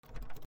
07 ドアを開ける(強)1
/ K｜フォーリー(開閉) / K52 ｜ドア－セット / ドア(室内_木製)2
マイクバリエーションあり